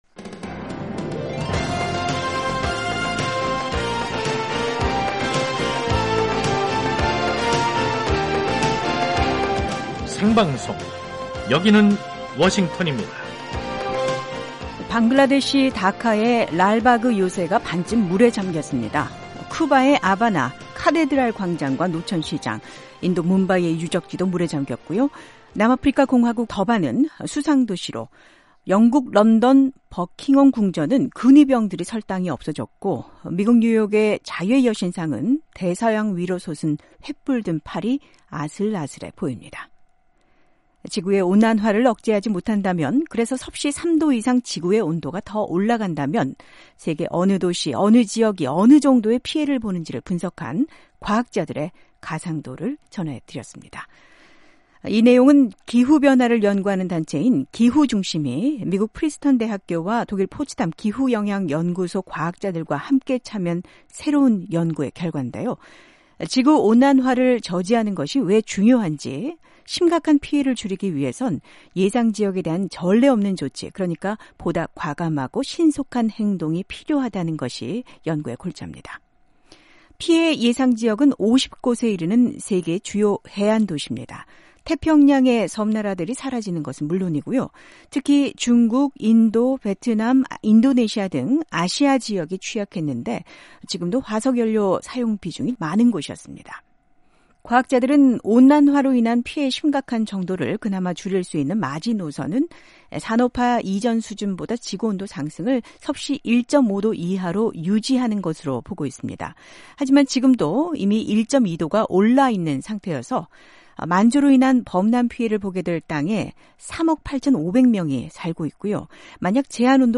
세계 뉴스와 함께 미국의 모든 것을 소개하는 '생방송 여기는 워싱턴입니다', 2021년 10월 15일 저녁 방송입니다. '지구촌 오늘'에서는 미국이 탈퇴 3년 만에 유엔 인권이사회 이사국으로 선출된 소식, '아메리카 나우'에서는 미국이 코로나 대응에 옳은 방향으로 가고 있다고 조 바이든 대통령이 밝힌 이야기 전해드립니다.